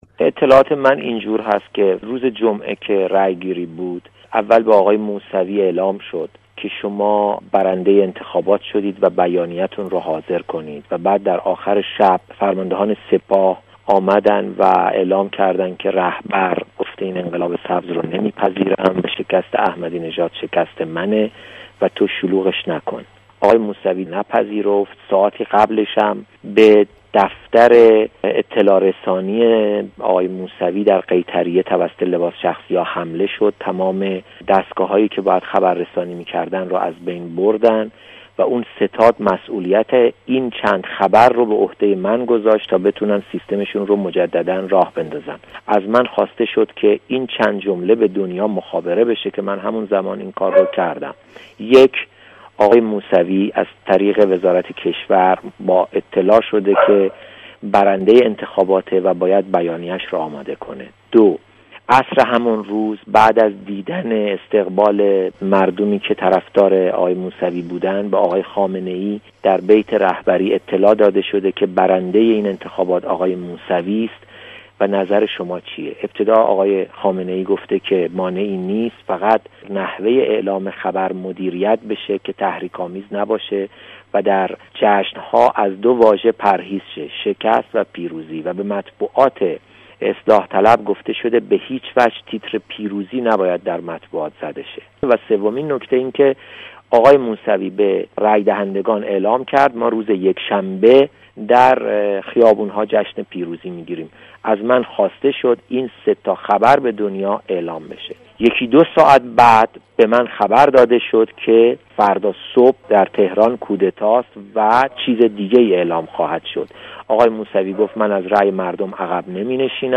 به همین دلیل برنامۀ این هفتۀ ما بدلیل شرایط ویژۀ این روزها مجموعه ای از مصاحبه های جداگانه با کسانی است که در این عرصه صاحب نظر بوده اند.